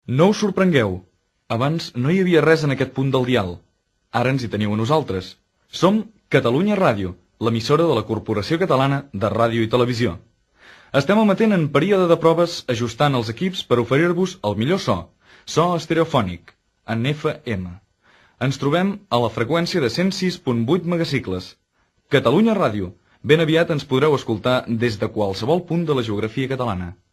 506be67cc38854bea3f75f7ca84eba3bab92b2e9.mp3 Títol Catalunya Ràdio Emissora Catalunya Ràdio Cadena Catalunya Ràdio Titularitat Pública nacional Descripció Identificació i freqüència de l'emissora a Barcelona, durant les emissions en proves.